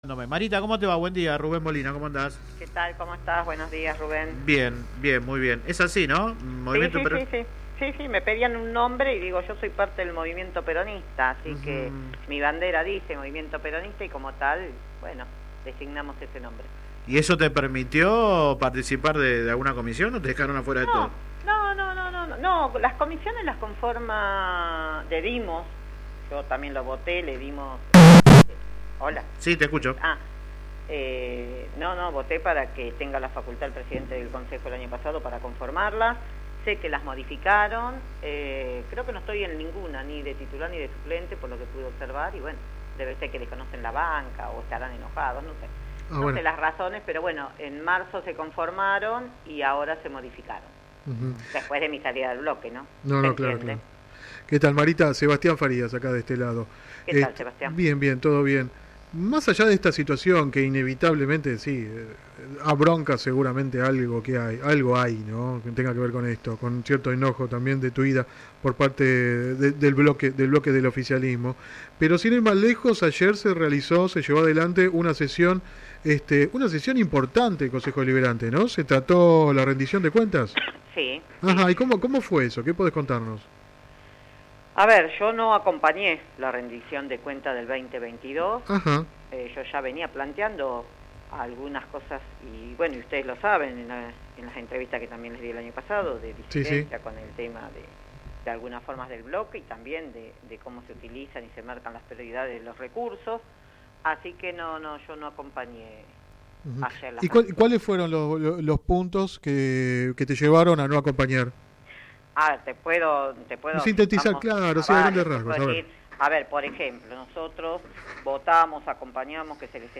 La edil habló en el programa radial Sin Retorno (lunes a viernes de 10 a 13 por GPS El Camino FM 90 .7 y AM 1260).
Click acá entrevista radial